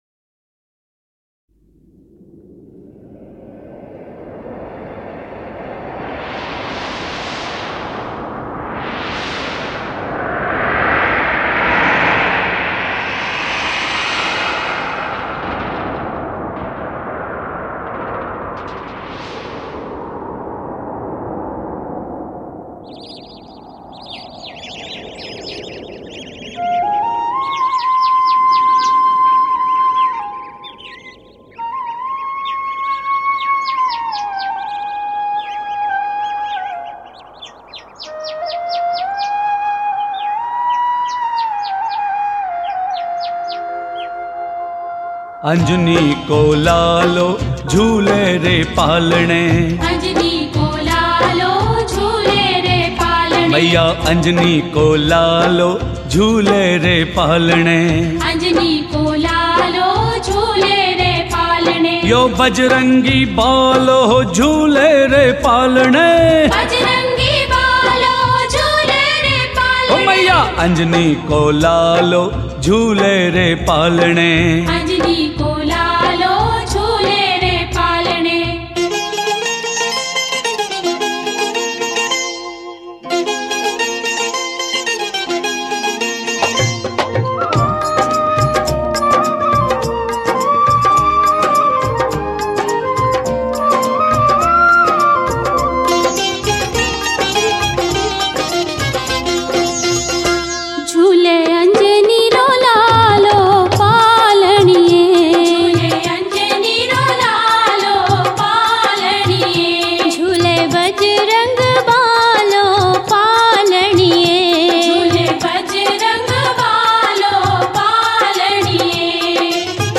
Rajasthani Songs
Balaji Bhajan